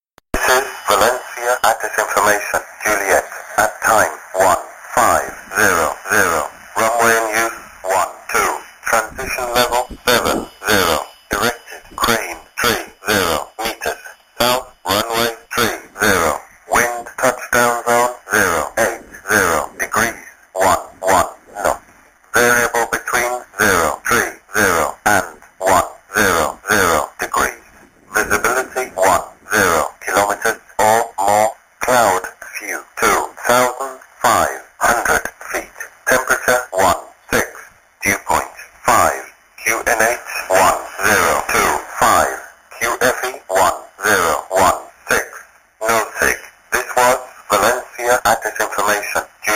Valencia ATIS information Juliet at time 1500